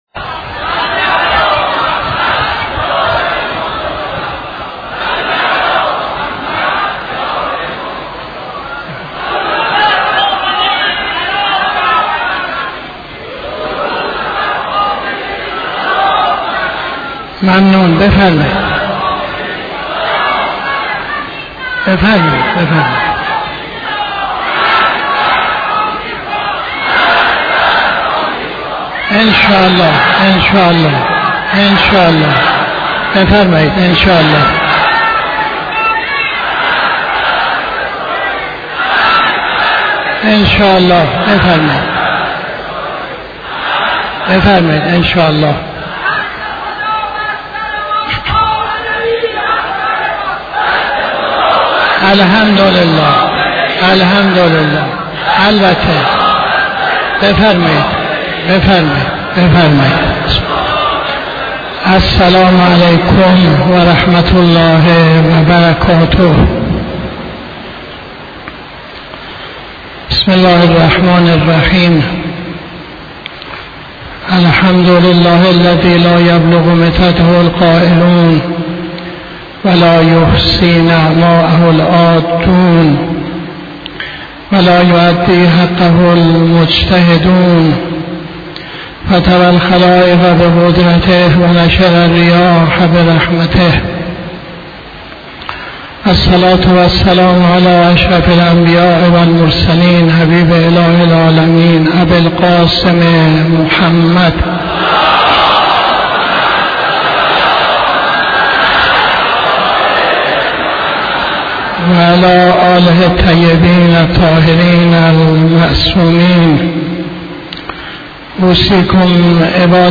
خطبه اول نماز جمعه 07-06-82